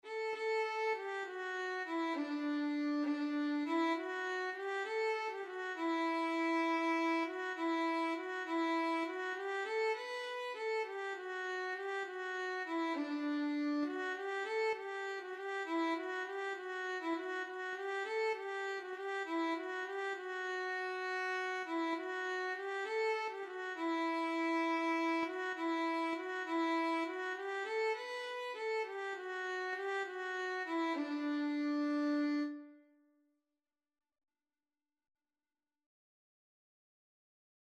Christmas Christmas Violin Sheet Music Carol of the Bagpipers
Violin (LARGE)
Traditional Music of unknown author.
Sicilian carol
D major (Sounding Pitch) (View more D major Music for Violin )
6/8 (View more 6/8 Music)
D5-B5